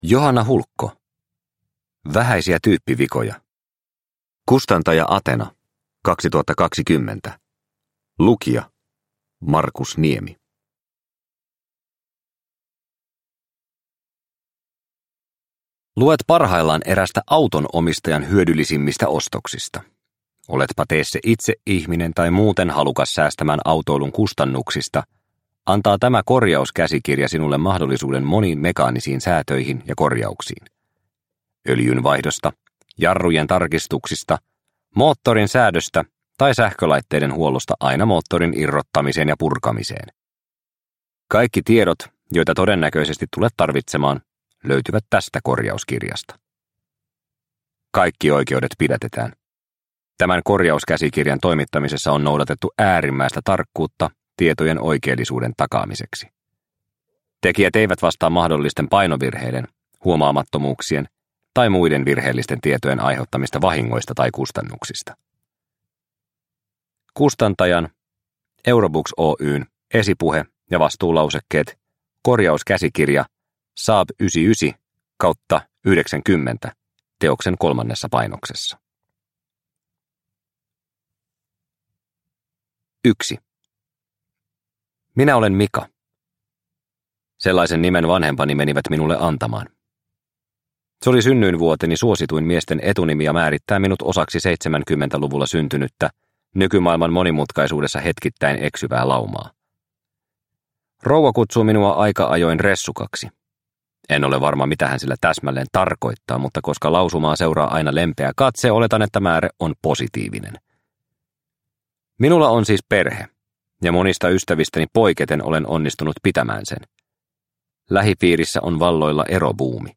Vähäisiä tyyppivikoja – Ljudbok – Laddas ner